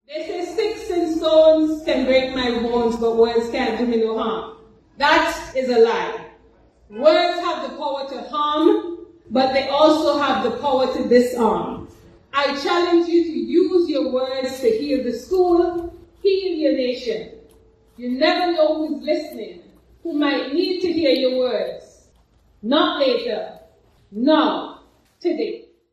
This is a snippet of Minister Nisbett during one of her visits: